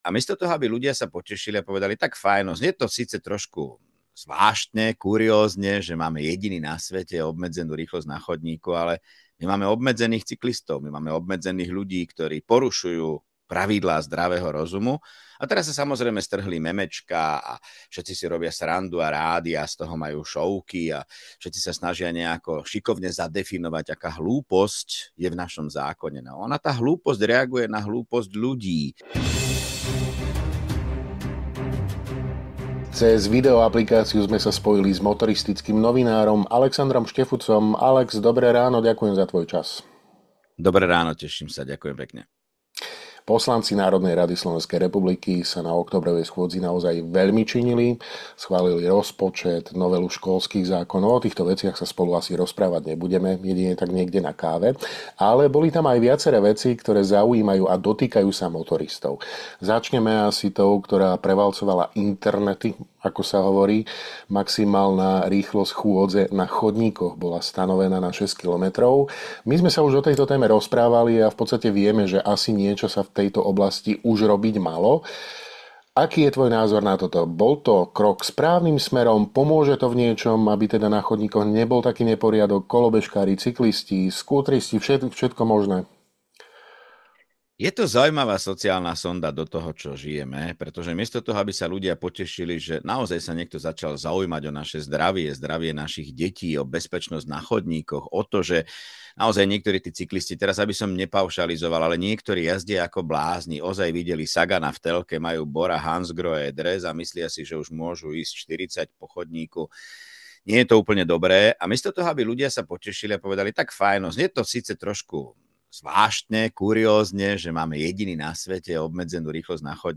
v rozhovore pre Hlavné správy vysvetľuje, prečo považuje stanovenie maximálnej rýchlosti na chodníkoch za krok správnym smerom. Vôbec totiž nejde o „zákon o chôdzi“, ale o snahu upratať chaos spôsobený kolobežkármi a cyklistami, ktorí sa po chodníkoch preháňajú ako na pretekoch. Ide o reakciu na realitu, nie o nezmysel a legislatíva len reaguje na správanie sa ľudí.